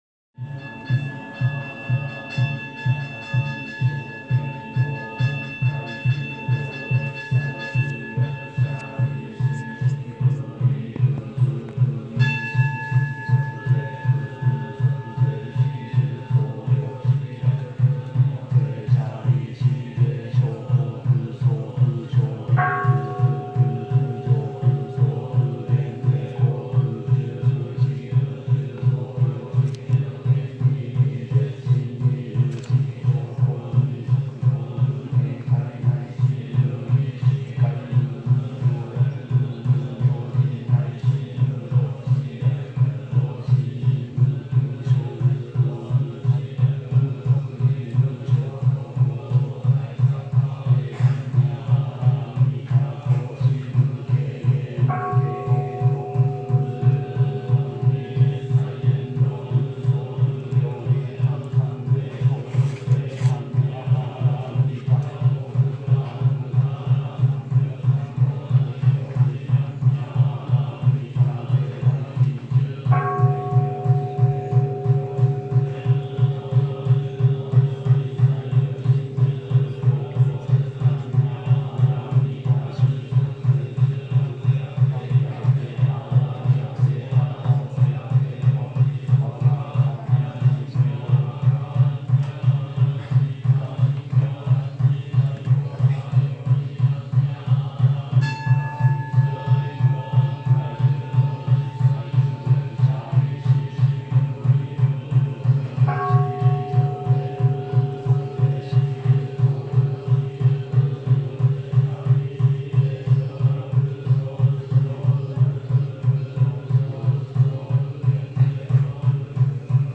Lots of chanting, with drums and bells.
Photos and video were expressly forbidden inside the temple, but I used my phone to record the audio.
8 minutes of Prayers
Kokedera Prayers.m4a